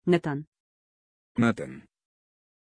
Pronunciation of Nathan
pronunciation-nathan-ru.mp3